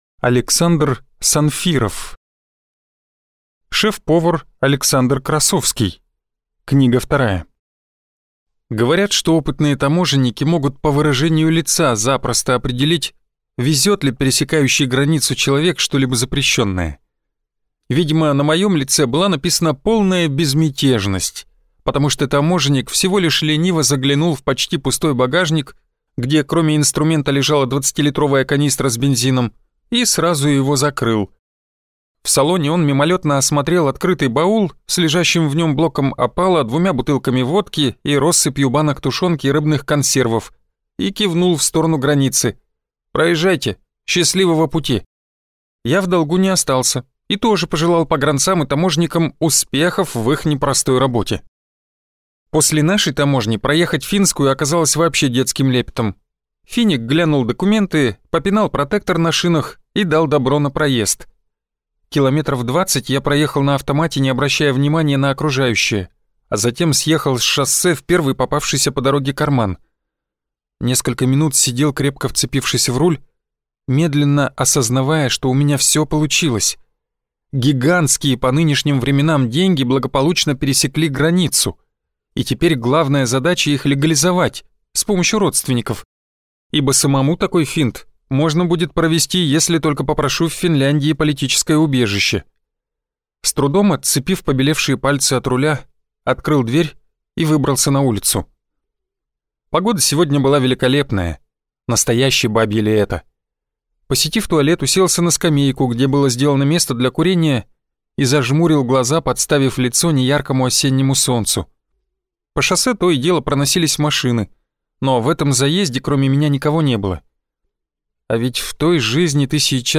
Аудиокнига Шеф-повар Александр Красовский 2 | Библиотека аудиокниг